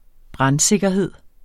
Udtale [ ˈbʁɑn- ]